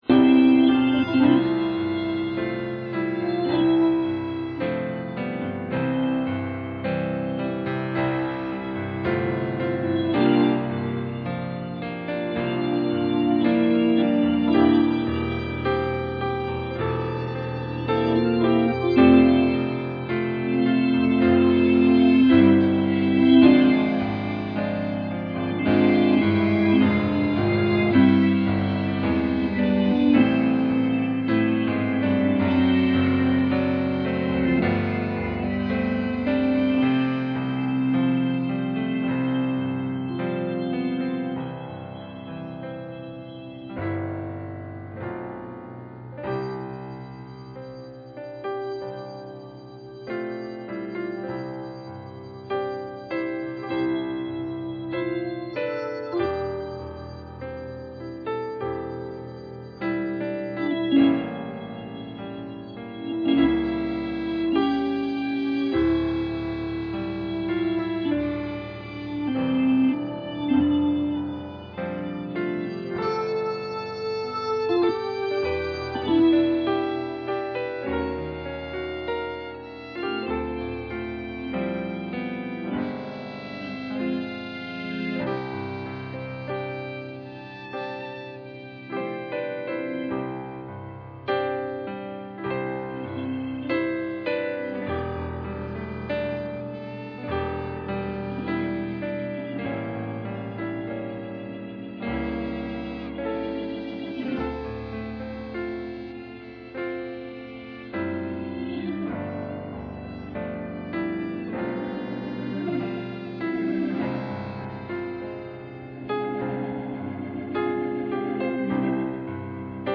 Joshua 10:12-14 Service Type: Sunday Morning %todo_render% « Galatians Chapter 4